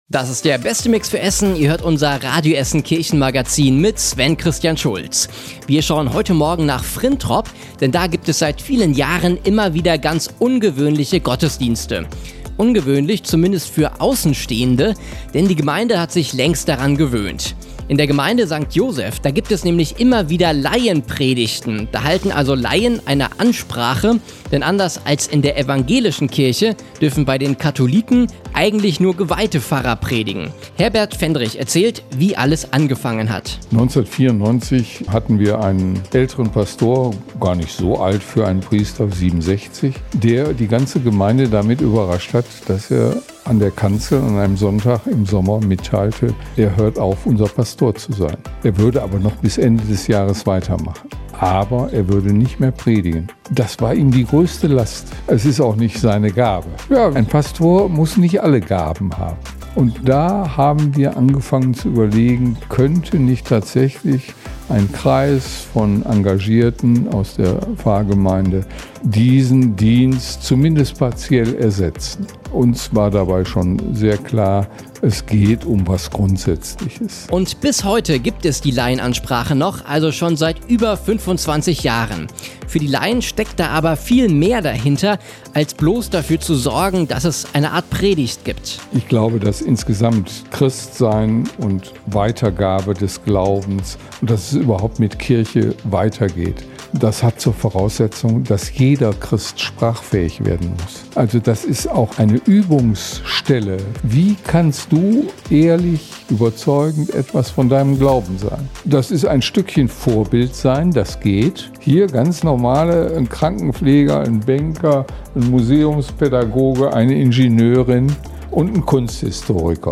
Wir waren bei den Vorbereitungen für eine solche Ansprache mit dabei und hier könnt Ihr unseren Beitrag nachhören: